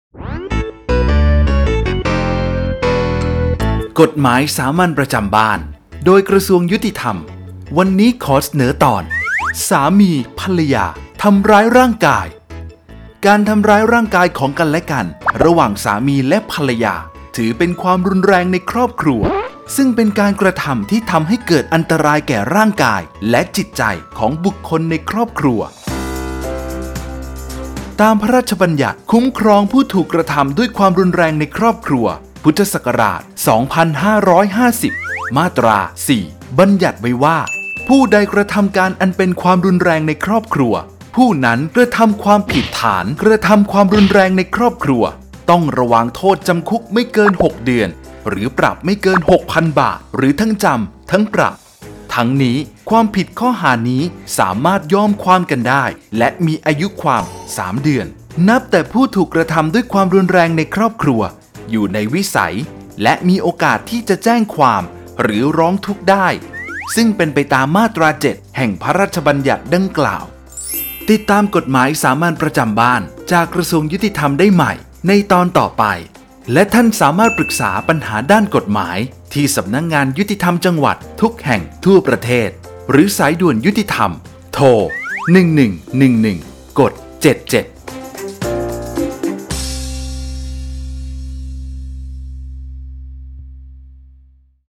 กฎหมายสามัญประจำบ้าน ฉบับภาษาท้องถิ่น ภาคกลาง ตอนสามี-ภรรยา ทำร้ายร่างกาย
ลักษณะของสื่อ :   บรรยาย, คลิปเสียง